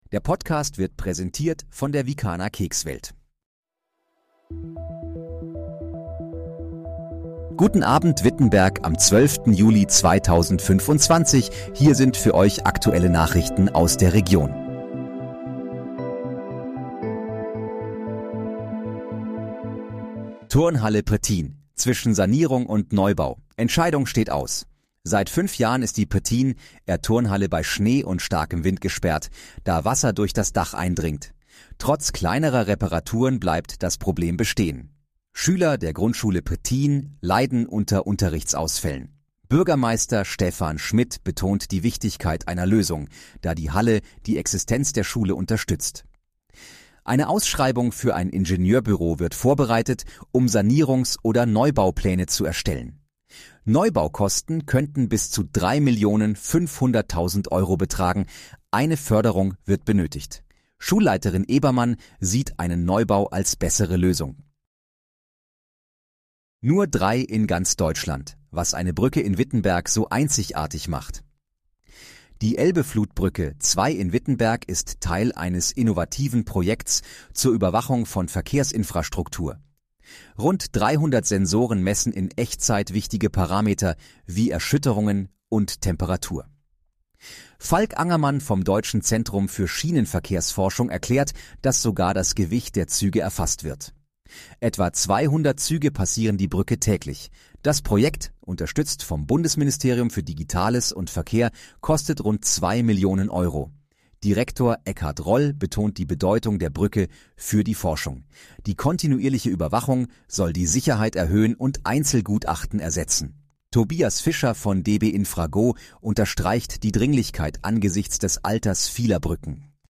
Guten Abend, Wittenberg: Aktuelle Nachrichten vom 12.07.2025, erstellt mit KI-Unterstützung
Nachrichten